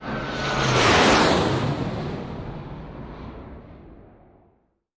AFX_F18_FLYBY_2_DFMG.WAV
F-18 Flyby 2